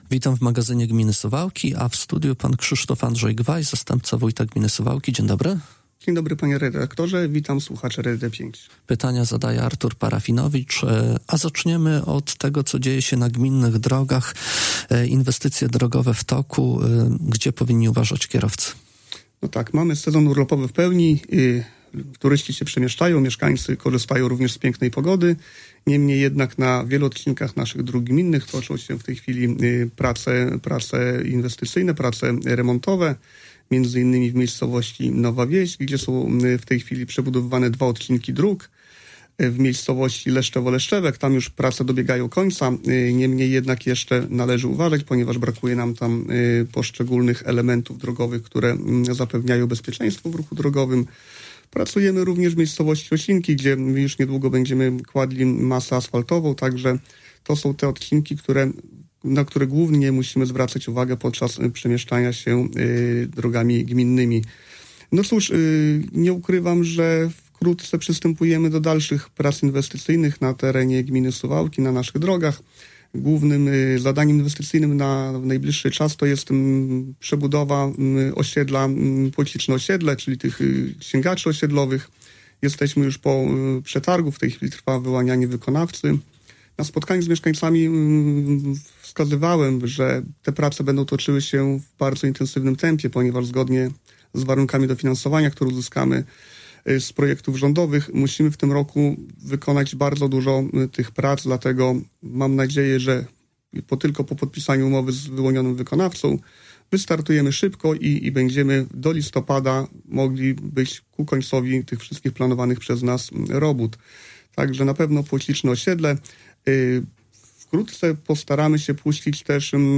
O inwestycjach drogowych, budowie remizy OSP Potasznia, kanalizacji w Piertaniach i zbliżających się dożynkach mówił dziś w Radiu 5 Krzysztof Andrzej Gwaj, zastępca wójta gminy Suwałki.